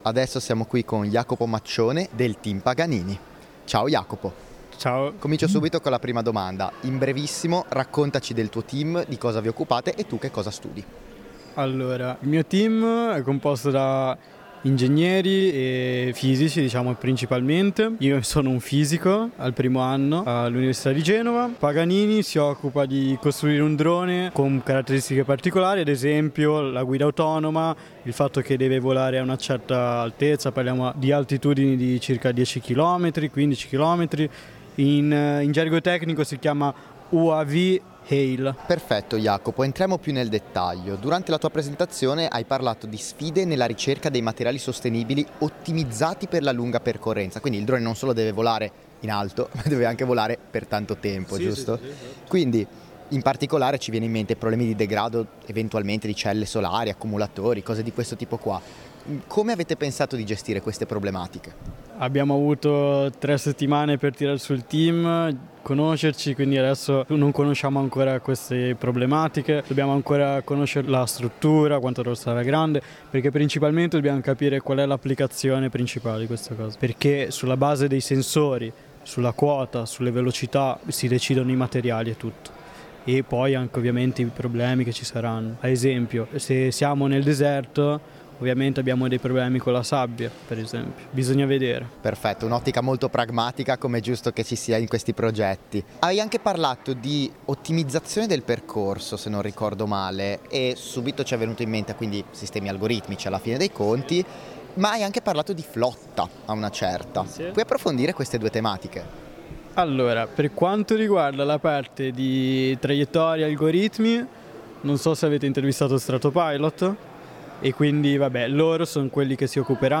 Intervista di